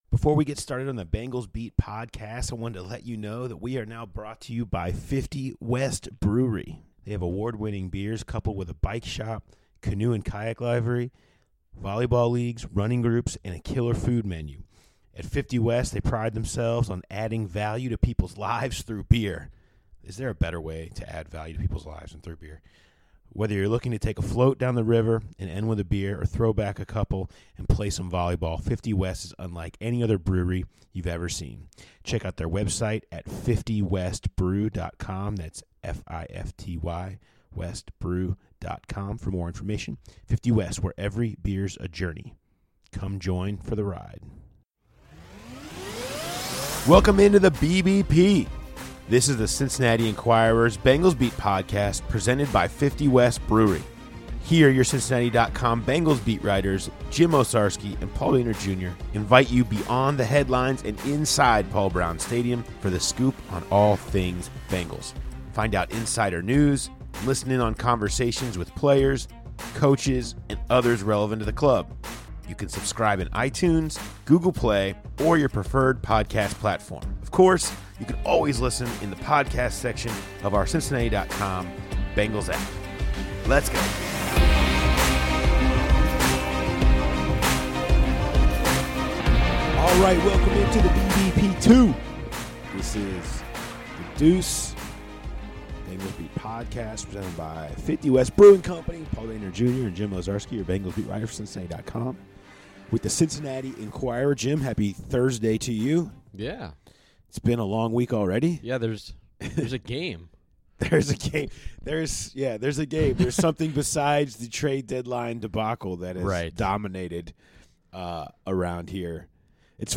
Hear from McCarron in his reaction to a wild Tuesday.